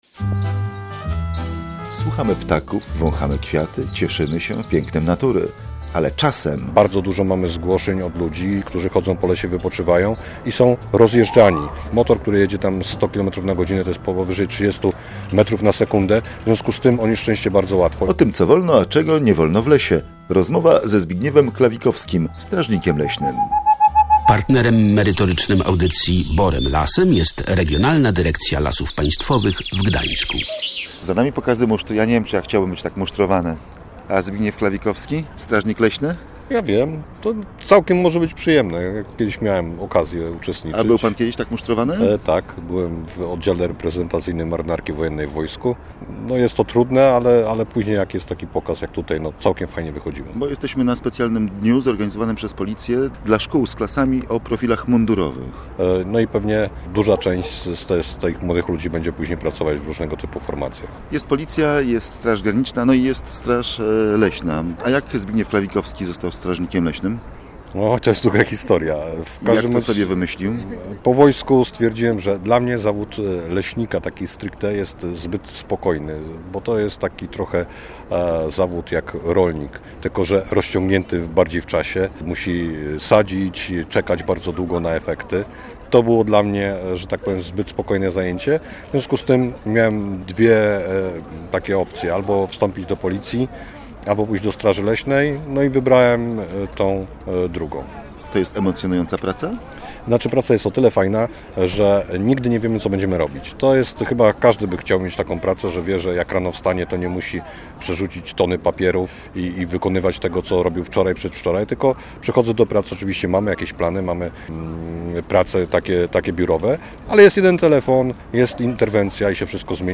Rozmowa o tym co wolno i czego nie wolno robić w lesie odbywa się na terenie Oddziału Prewencyjnego Policji w Matarni.